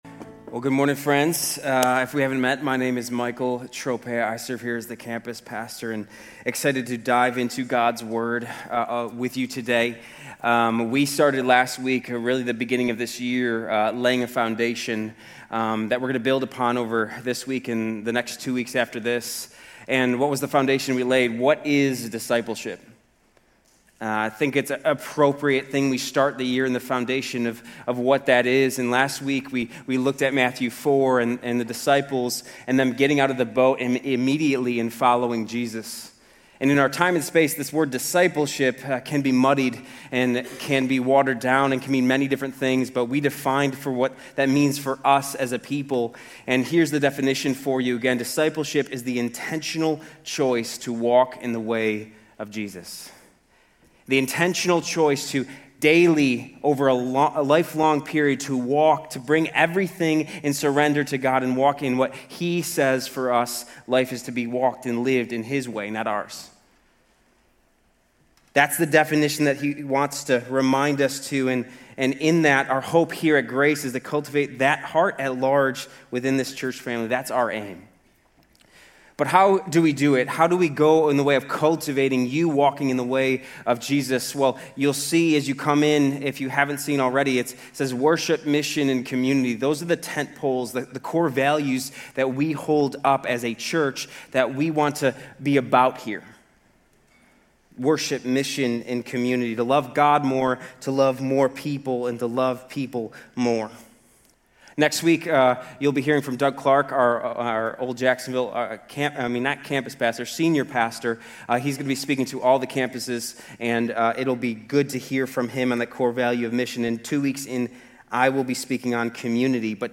Grace Community Church University Blvd Campus Sermons 1_11 University Blvd Campus Jan 12 2026 | 00:33:50 1x 00:00 / 00:33:50 Subscribe Share RSS Feed Share Link Embed